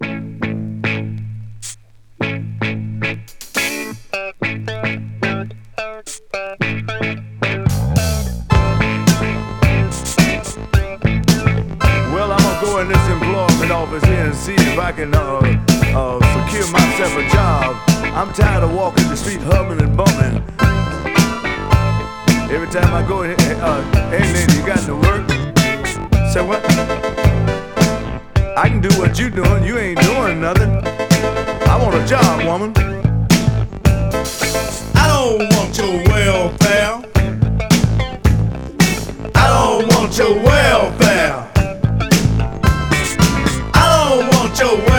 Rock, Rock'N'Roll, Funk　France　12inchレコード　33rpm　Stereo